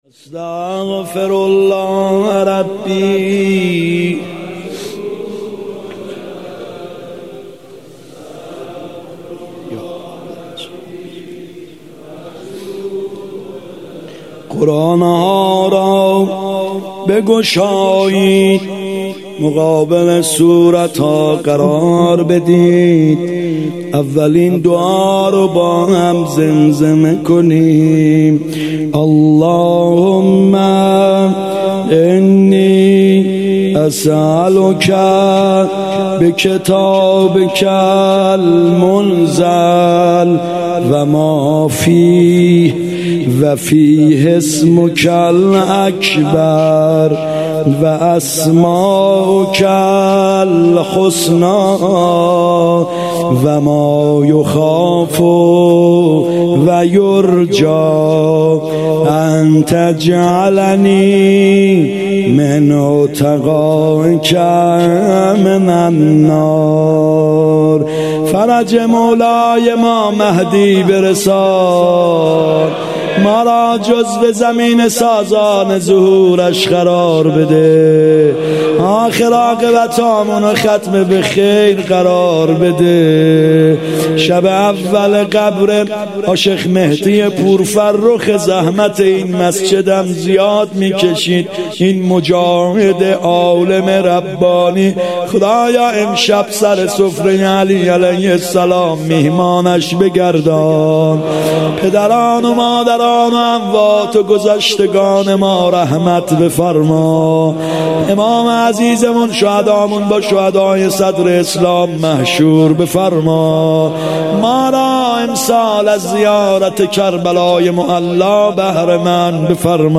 مناجات (قران به سر)
هیات یامهدی عج (رمضان98)